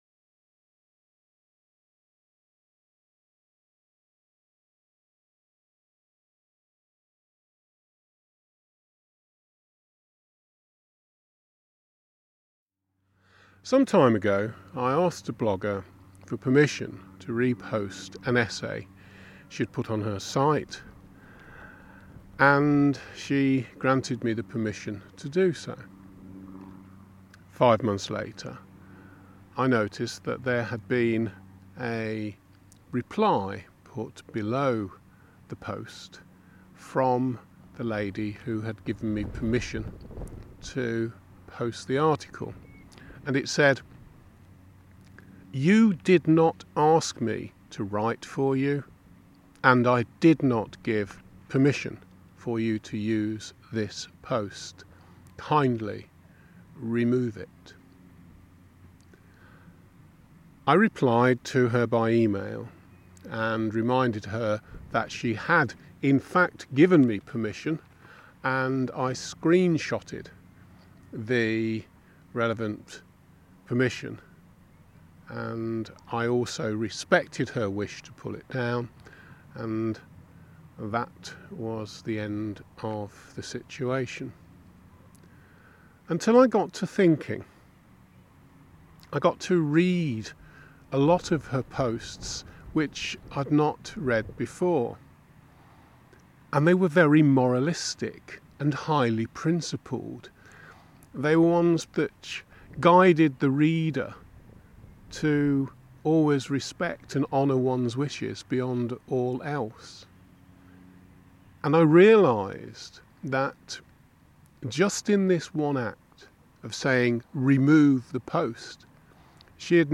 The recording is twenty minutes long and unedited!
It is a vast expanse of sand and sea and wind. During the recording, you’ll hear the wind and drops of rain tapping on my umbrella.
let-down-inner-thoughts-at-anderby.mp3